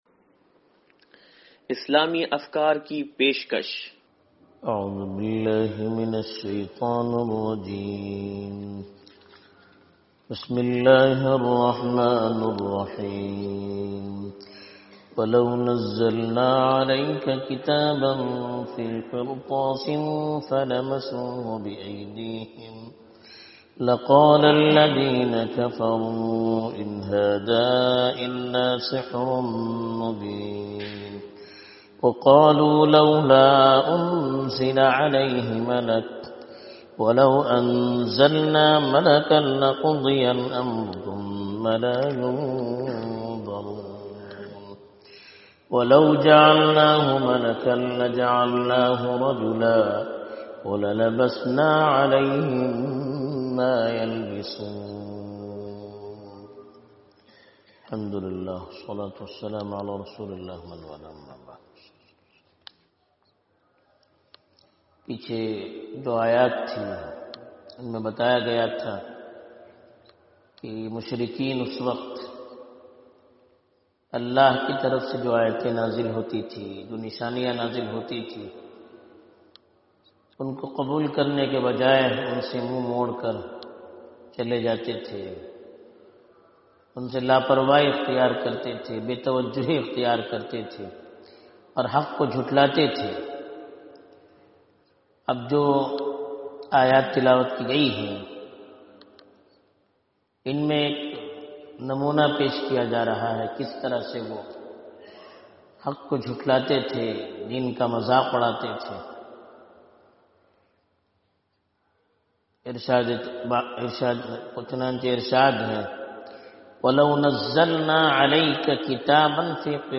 درس قرآن نمبر 0504